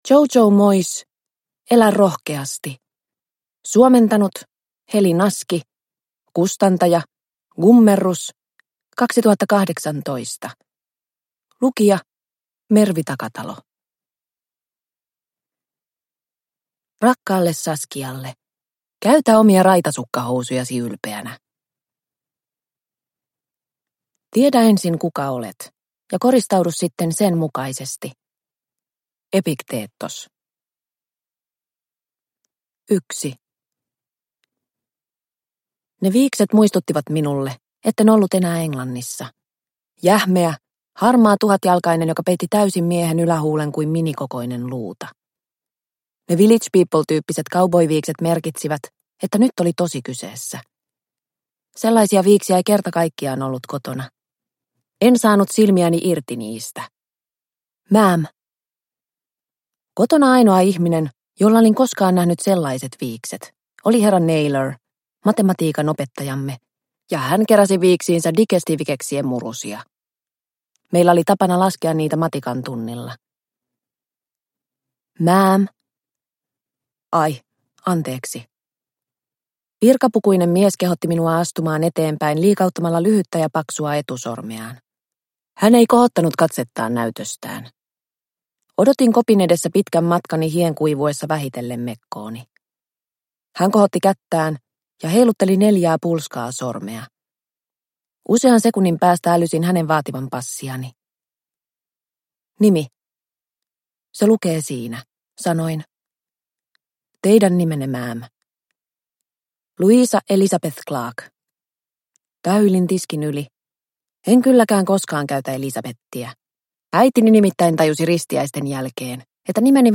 Elä rohkeasti – Ljudbok – Laddas ner